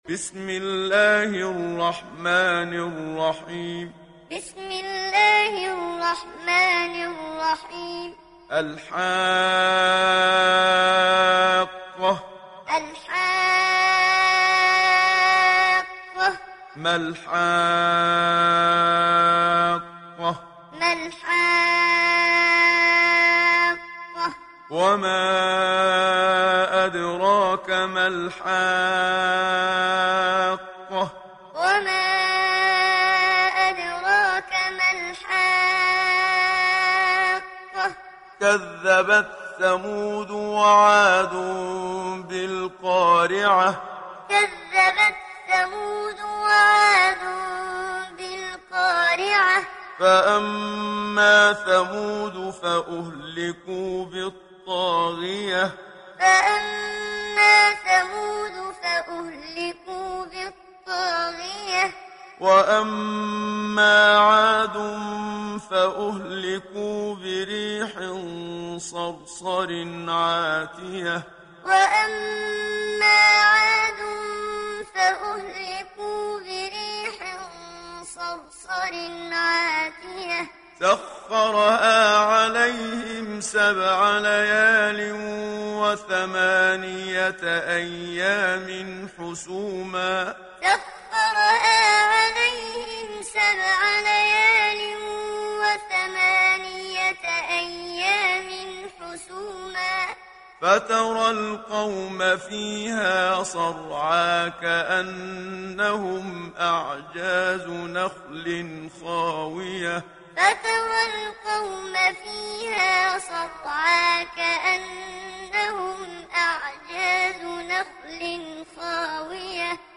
دانلود سوره الحاقه mp3 محمد صديق المنشاوي معلم روایت حفص از عاصم, قرآن را دانلود کنید و گوش کن mp3 ، لینک مستقیم کامل
دانلود سوره الحاقه محمد صديق المنشاوي معلم